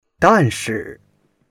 dan4shi4.mp3